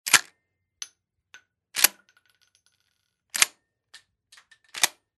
Звуки дробовиков
Заряжаем дробовик правильно